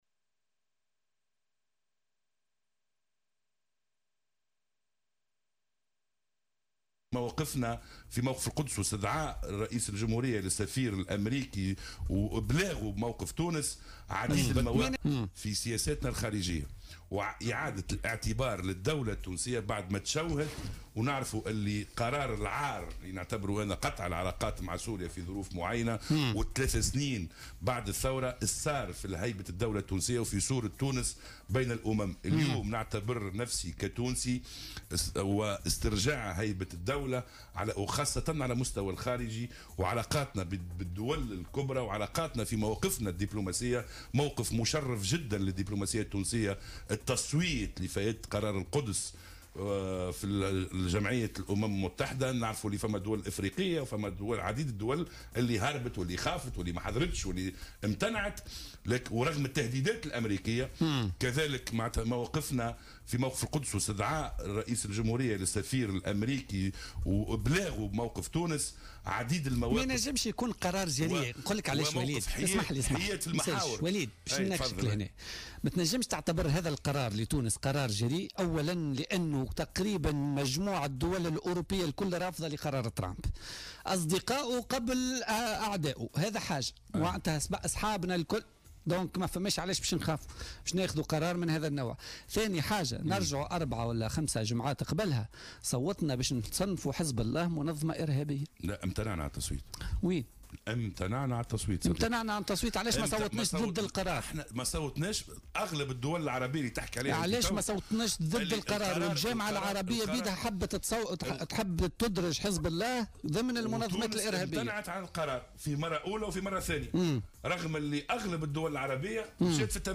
أكد النائب بالبرلمان وليد جلاد ضيف بولتيكا اليوم الأربعاء 27 ديسمبر 2017 أن الموقف الديبلوماسي التونسي كان مشرفا للغاية بالتصويت ضد قرار ترامب حول القدس بالأمم المتحدة مضيفا أن هذا القرار يعيد الاعتبار لهيبة الدولة و للدبلوماسية التونسية.